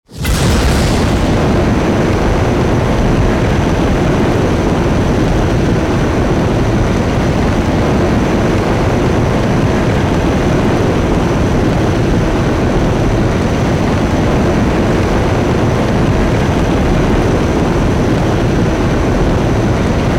cookoff_high_pressure.ogg